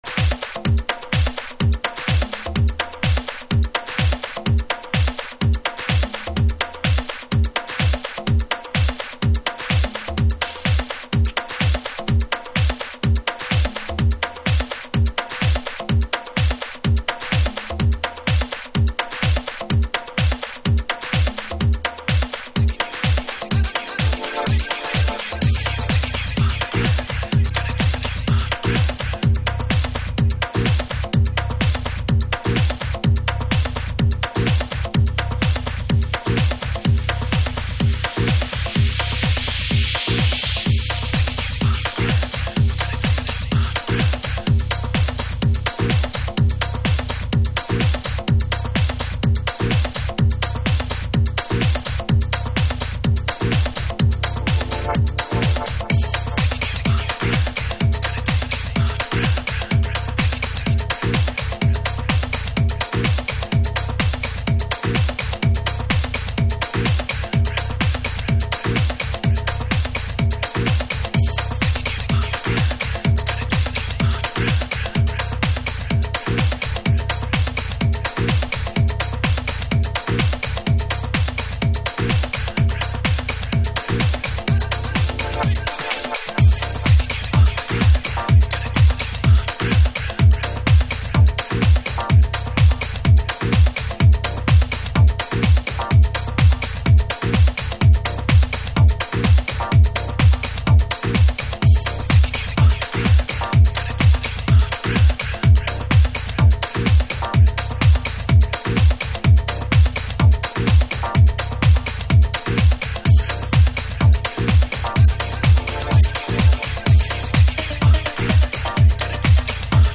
House / Techno
スウィングするドラミングとシンプルなメッセージのリフレインが強く印象的なフロアを作り出します。